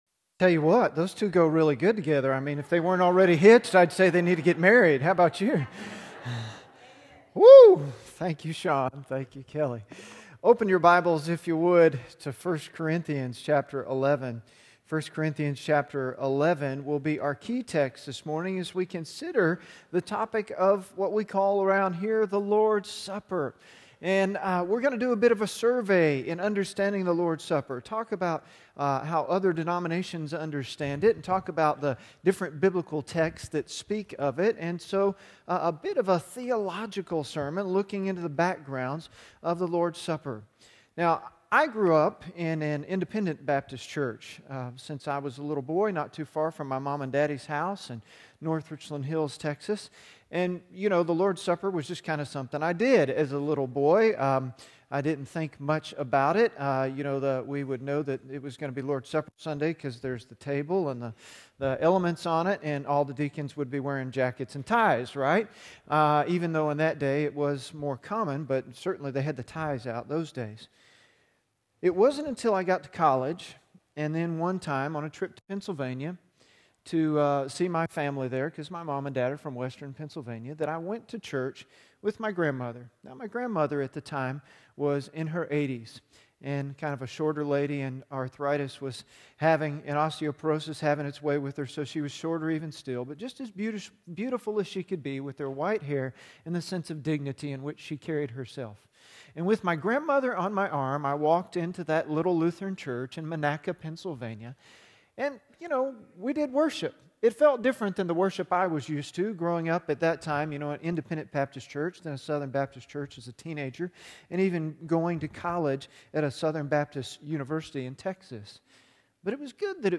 1 Corinthians 11:23-29 Sermon notes on YouVersion Understanding the Lord's Supper